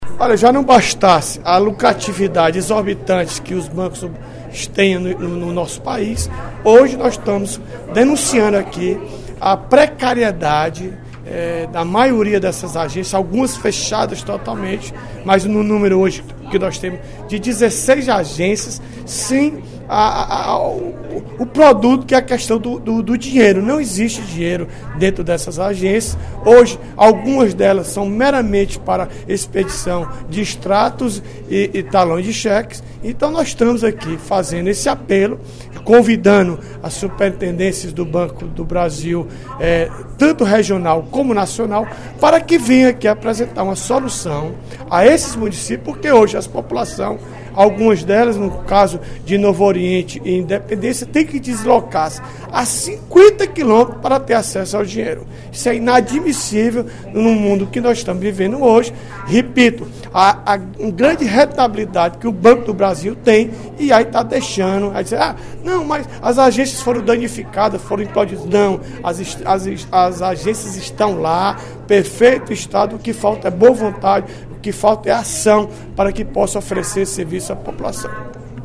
O deputado Odilon Aguiar (PMB) anunciou, no primeiro expediente da sessão plenária desta terça-feira (21/06), que a Comissão de Defesa do Consumidor, a qual preside, promoverá audiência pública para discutir a situação das agências do Banco do Brasil nos municípios do Interior que não estão prestando atendimento integral.